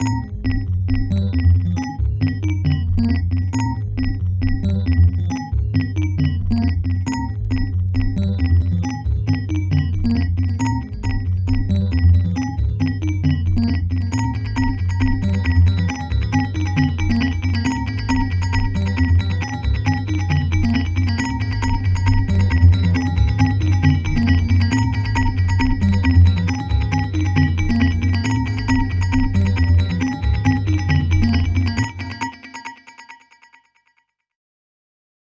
136 Bell Arp-C.wav